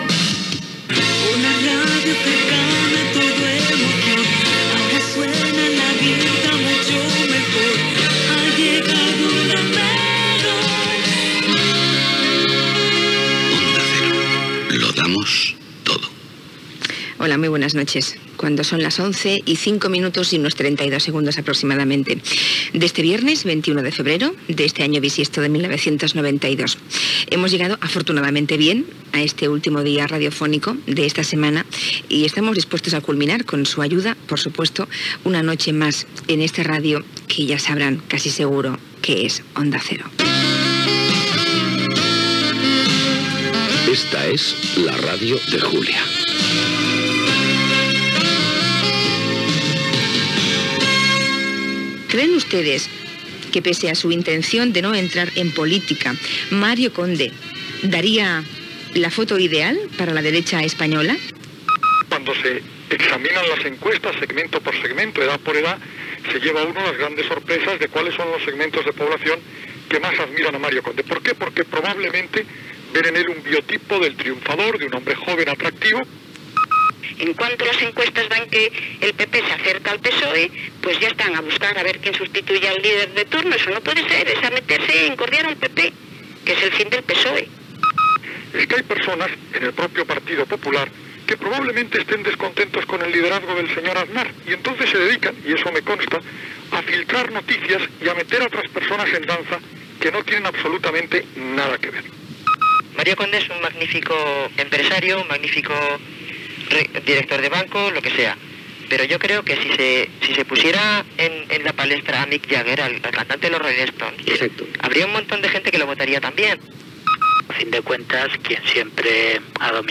Promoció de la cadena, presentació del programa, indicatiu del programa
Gènere radiofònic Info-entreteniment